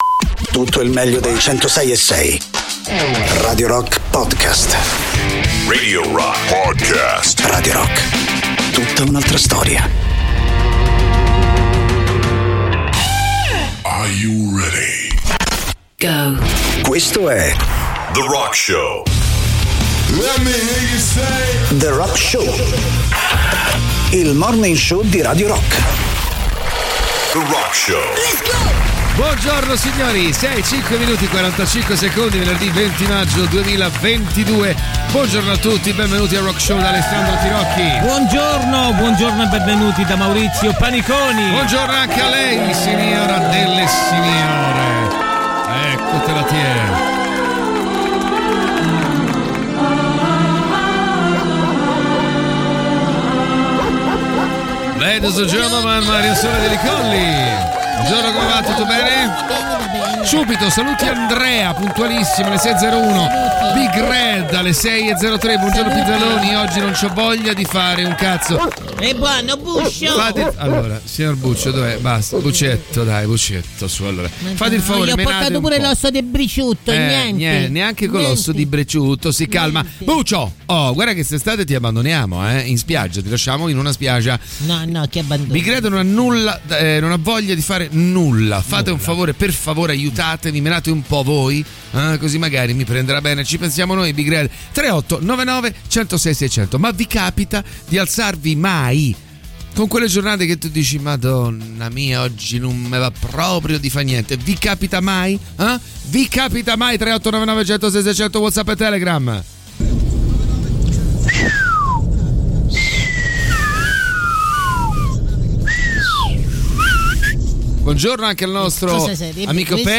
in diretta dal lunedì al venerdì